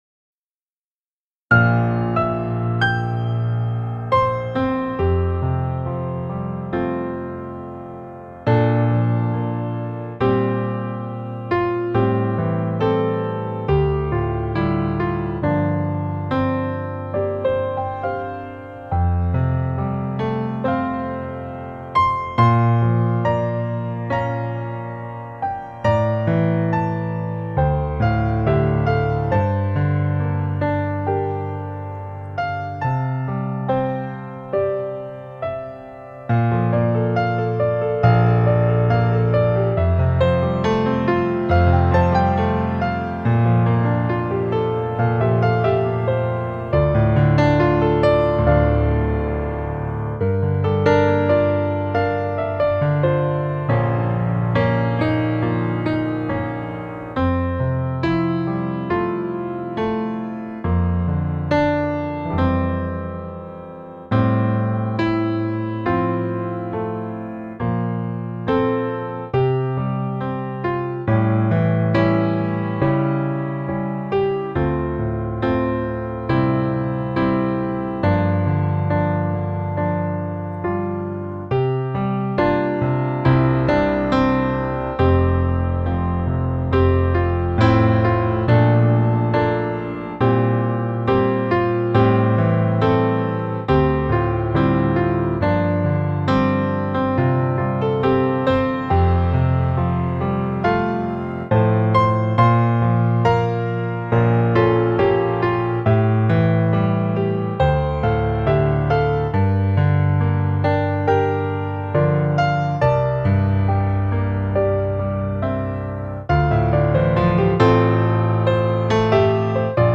Arsip File Multimedia Ibadah Gereja Kristen Jawa Ambarrukma Yogyakarta.
Sore Nologaten - Iringan Duet - Bukti KasihMu_audio.mp3